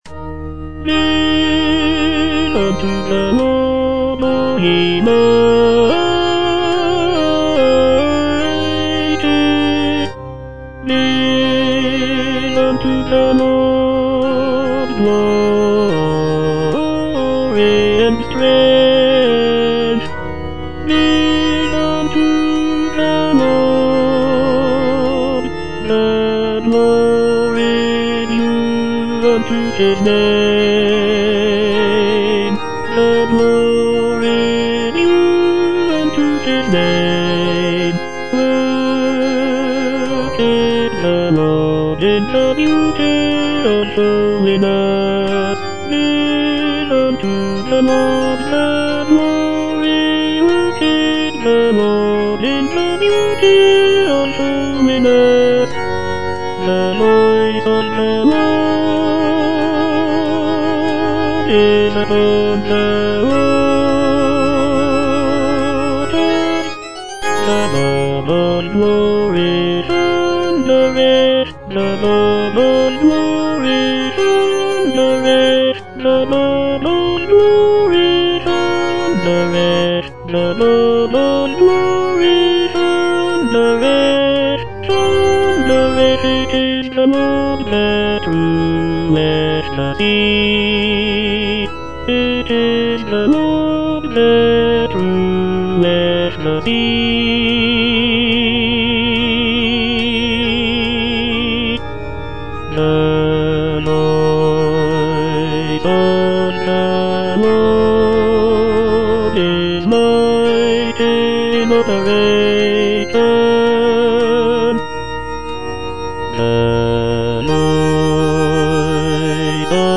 E. ELGAR - GIVE UNTO THE LORD Tenor I (Voice with metronome) Ads stop: auto-stop
The work is set for double chorus and orchestra, and features a majestic and triumphant sound that is characteristic of Elgar's music.